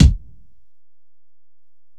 Kick (9).wav